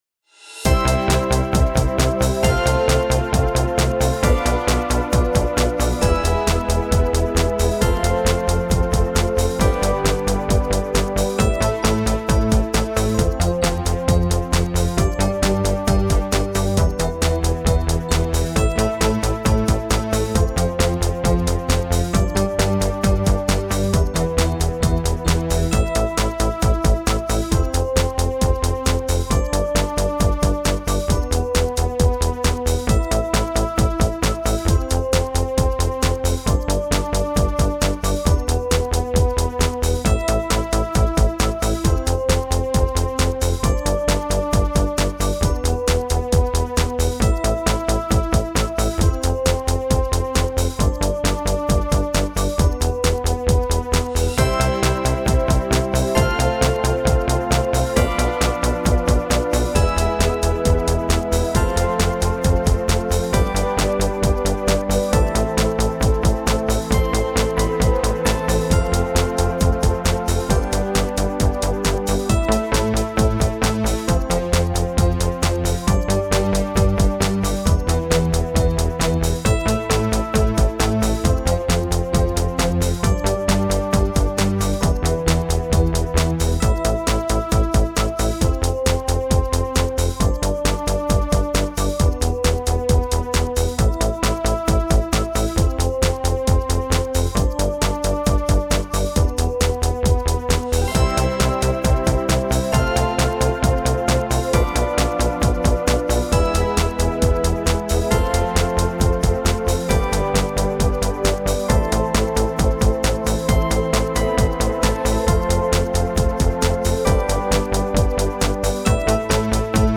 Übungsaufnahmen - Rent
Runterladen (Mit rechter Maustaste anklicken, Menübefehl auswählen)   Rent (Playback)